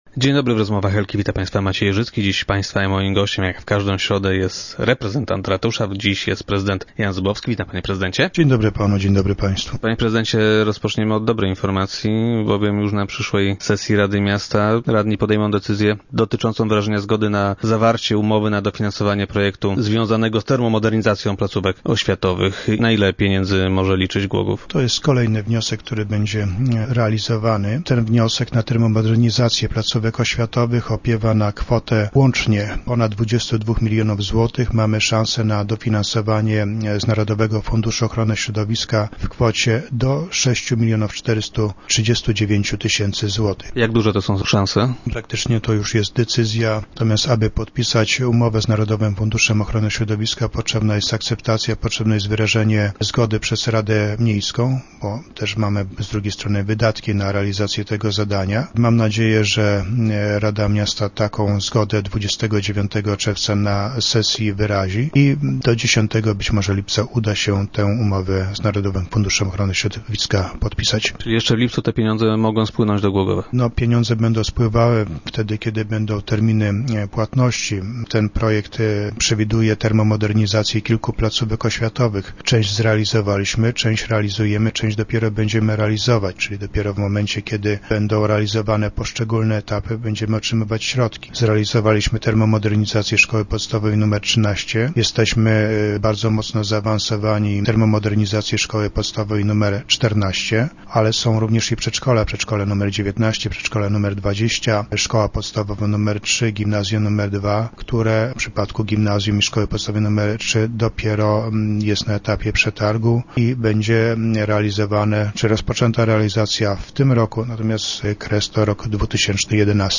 Na podpisanie stosownej umowy z Narodowym Funduszem Ochrony Środowiska muszą wyrazić zgodę miejscy radni. - To nie są jedyne pieniądze o jakie się staramy - zapewnia prezydent Jan Zubowski, który był dziś gościem Rozmów Elki.